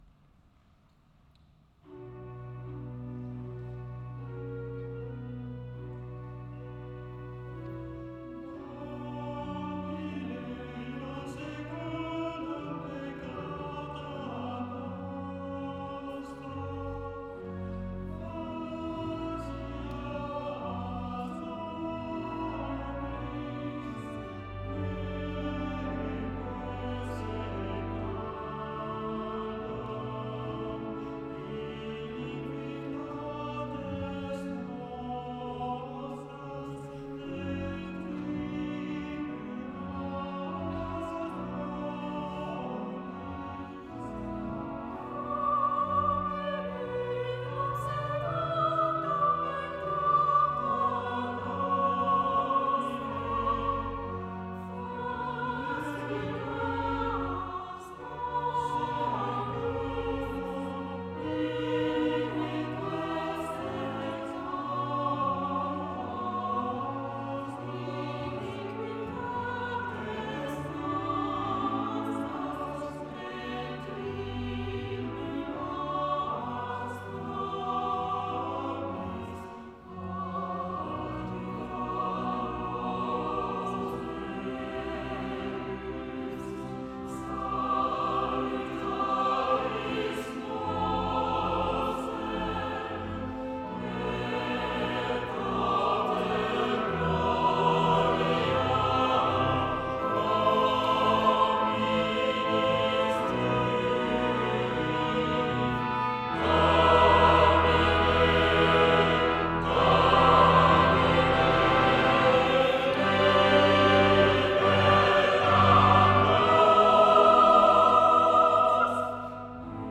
Prononciation gallicane du latin par respect de la tradition à l'époque de la création de l'oeuvre.